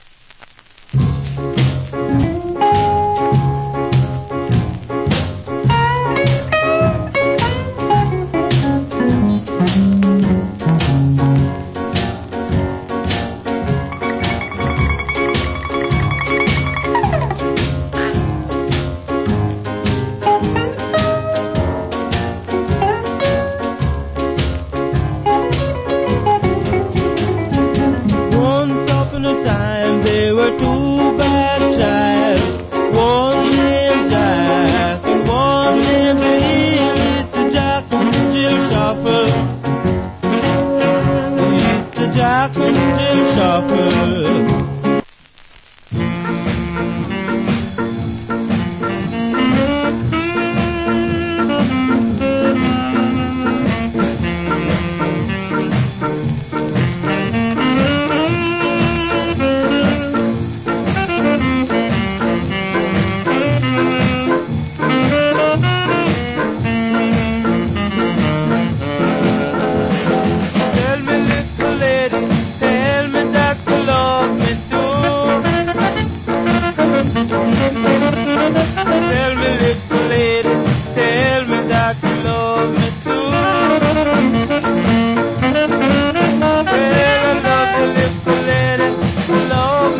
Ja R&B Vocal.